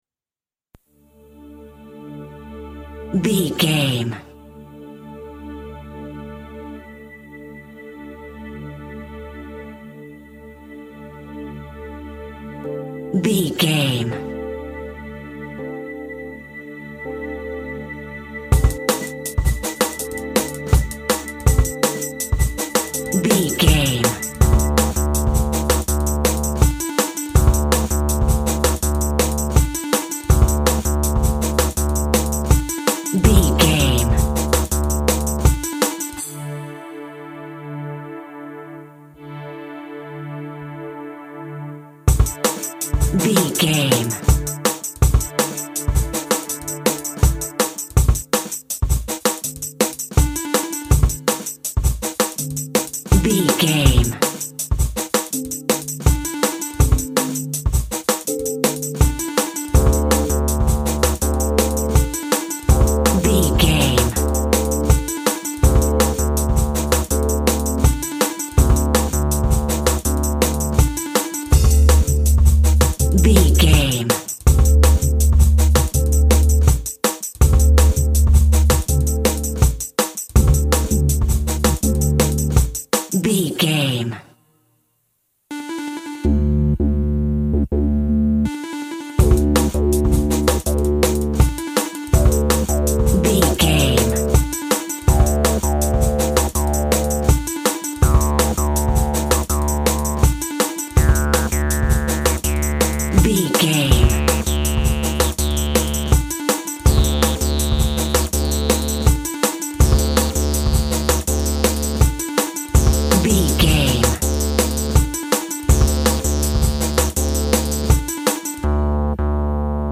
Ionian/Major
D
Fast
groovy
uplifting
bouncy
futuristic
frantic
drum machine
synth lead
synth bass
electronics